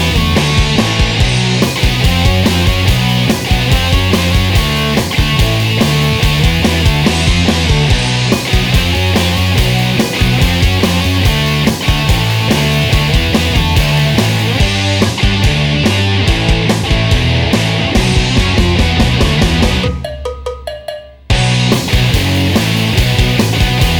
Minus Guitars Indie / Alternative 3:09 Buy £1.50